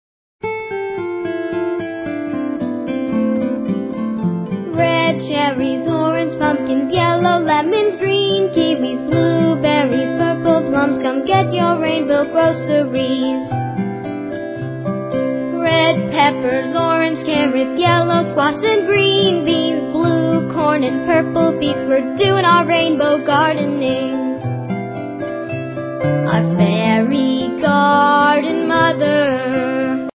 lively story-songs